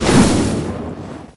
bar_fire_hit_01.ogg